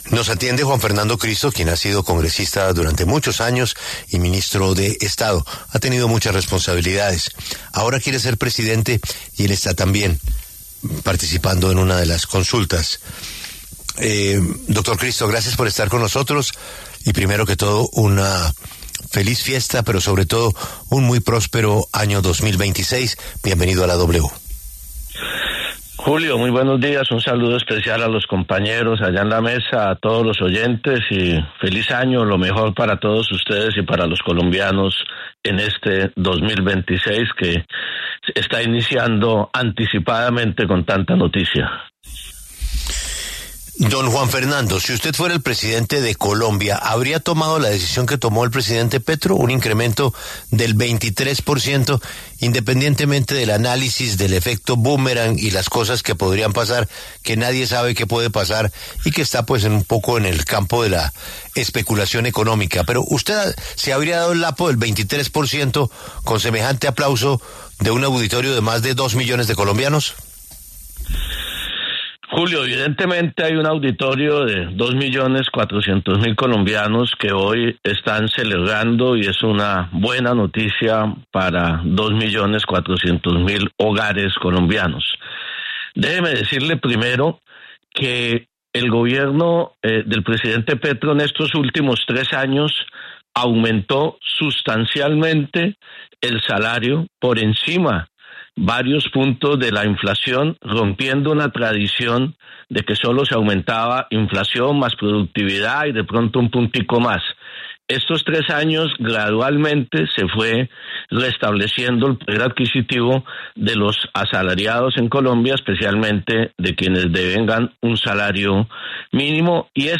En diálogo con La W, Juan Fernando Cristo, precandidato presidencial, se refirió al aumento del salario mínimo en un 23.7% para el 2026.